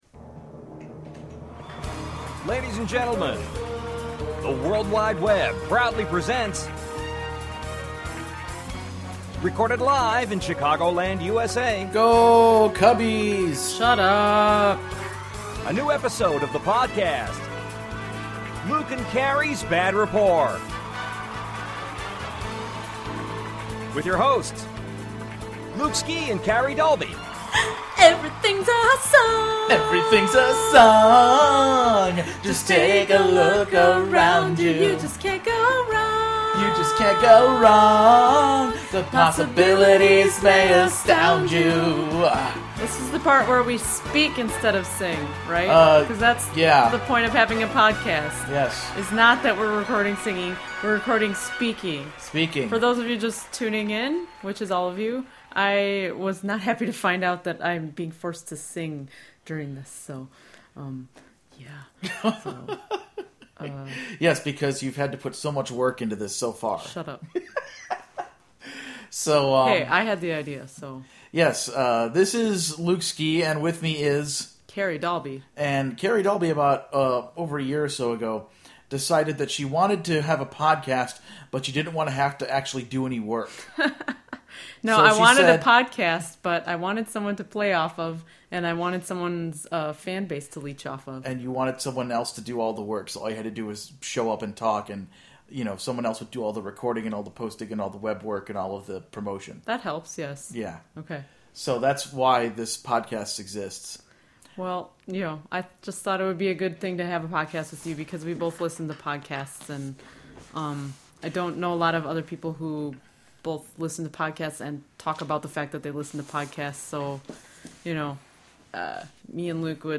the groovy opening & closing themes